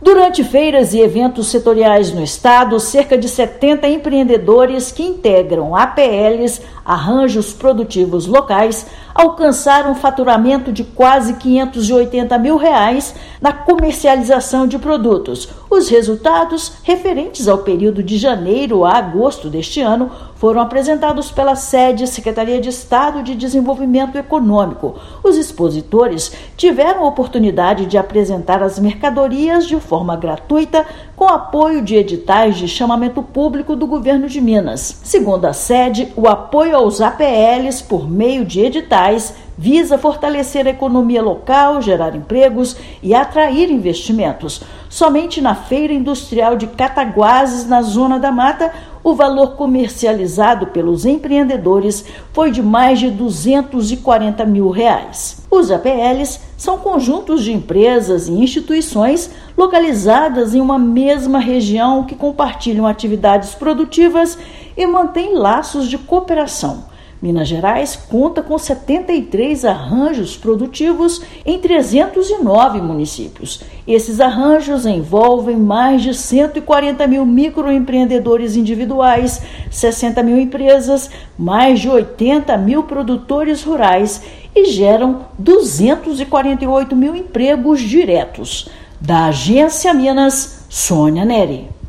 Quase 70 empreendedores integrantes de APLs tiveram a oportunidade de expor produtos, de forma gratuita, durante os eventos já realizados no ano. Ouça matéria de rádio.